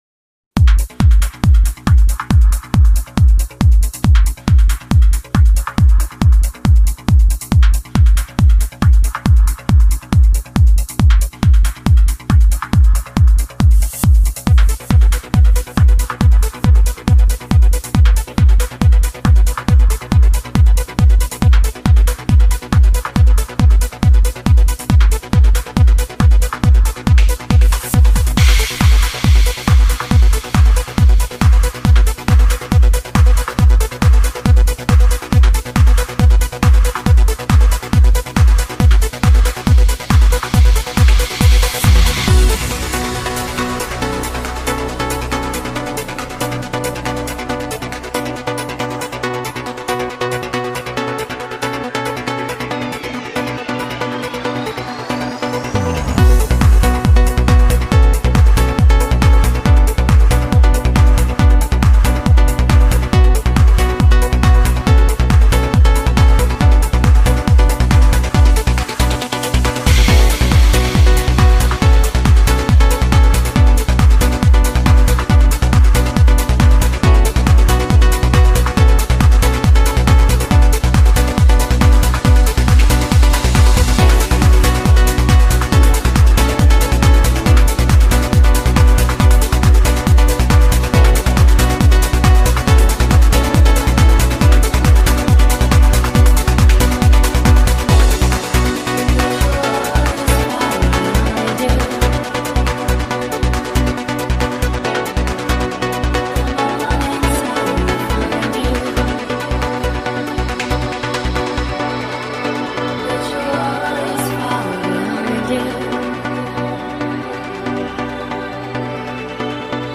DJ Mix With 12 Trance Songs